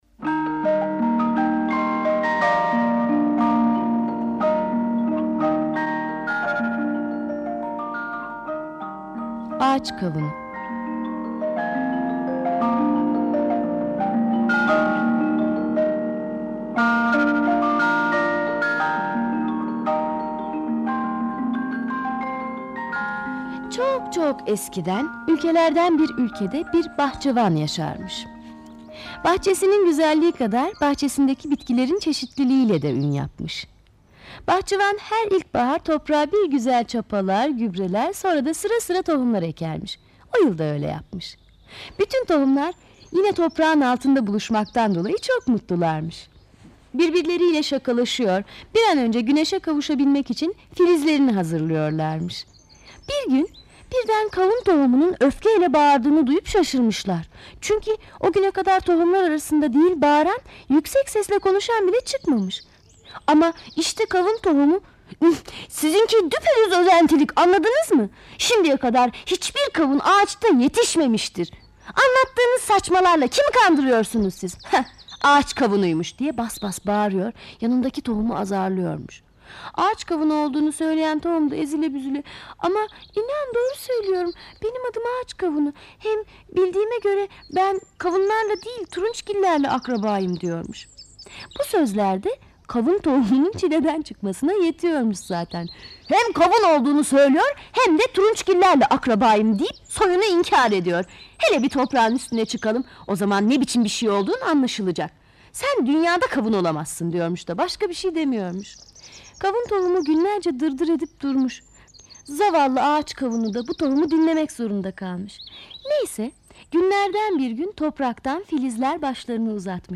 Ağaç kabuğu sesli masalı, mp3 dinle indir
Sesli Çocuk Masalları